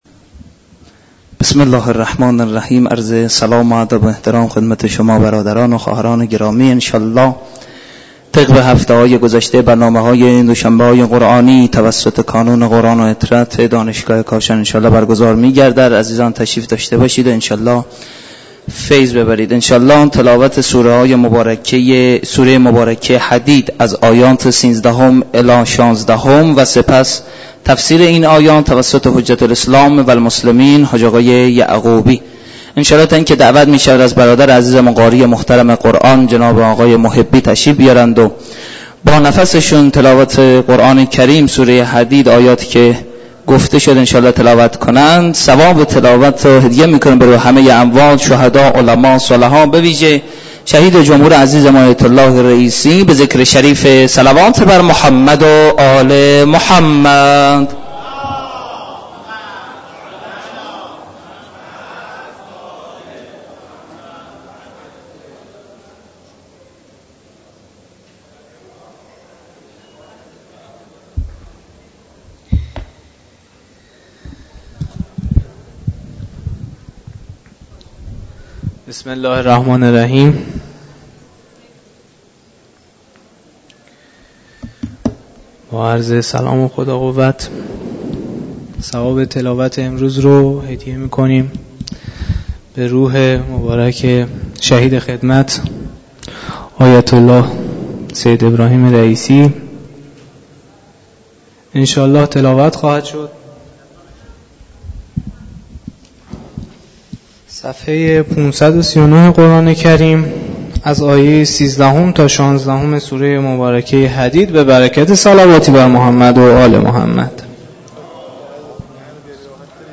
برگزاری مراسم معنوی دوشنبه های قرآنی در مسجد دانشگاه کاشان